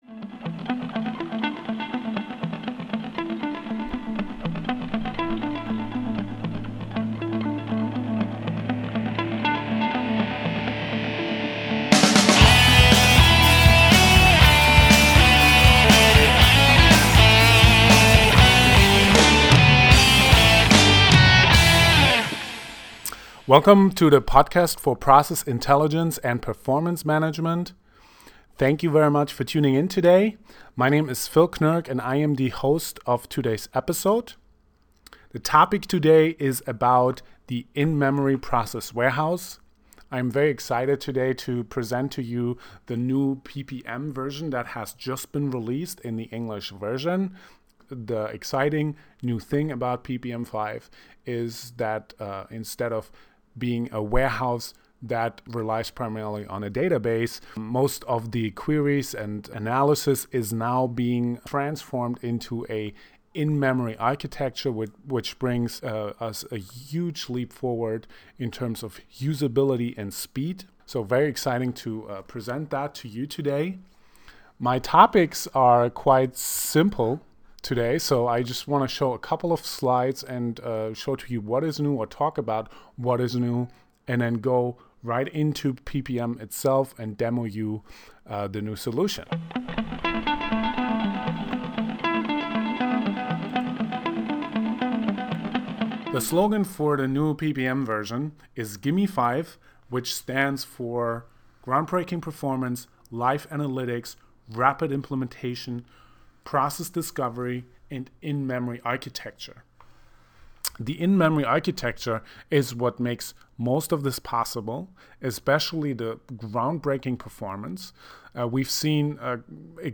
The audio version comes without the visual presentations and software demo.